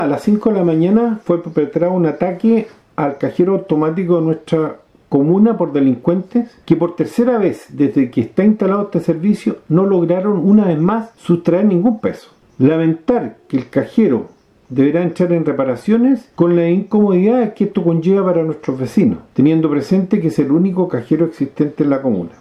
El hecho quedó registrado en las cámaras de seguridad del sector, donde los desconocidos abrieron la válvula de un balón de gas generando una explosión y posterior incendio, con la intención de robar el dinero del cajero, algo que no lograron, según precisó el alcalde, Luis Alberto Muñoz